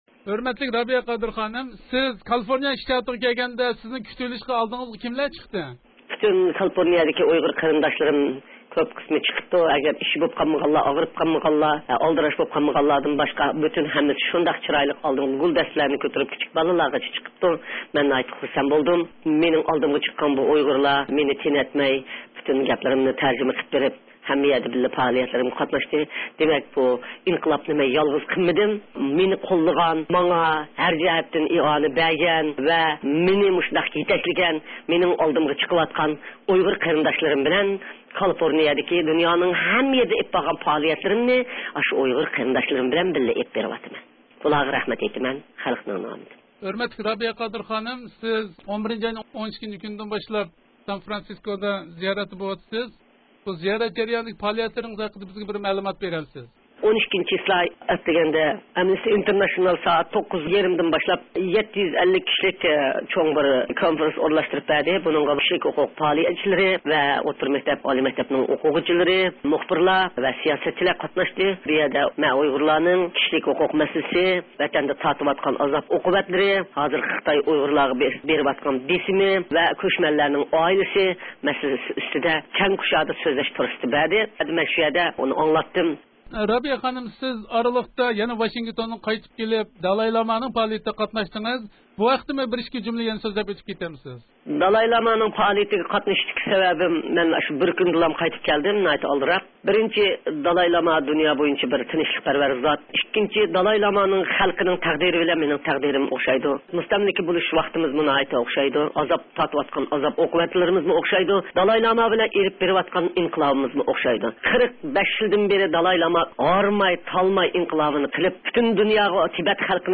رابىيە قادىر خانىم بىلەن ئۆتكۈزگەن سۆھبىتىدىن ئاڭلاڭ.